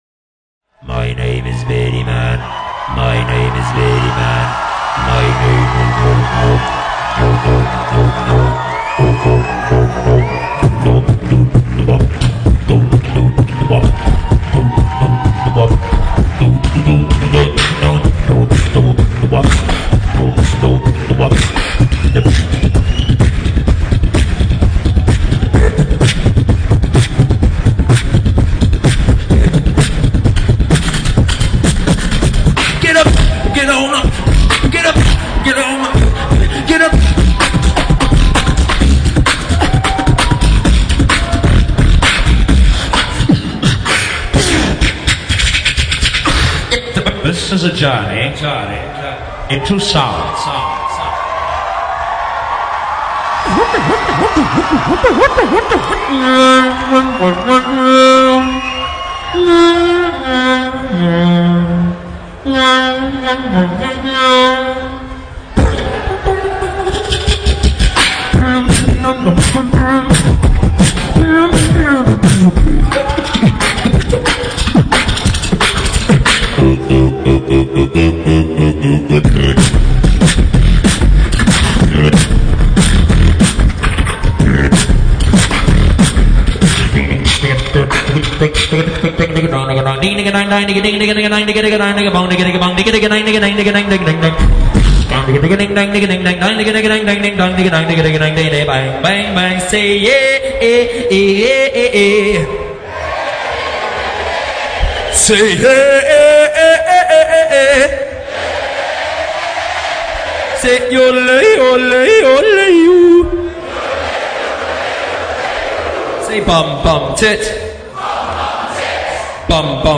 DubStep (Дабстеп) Название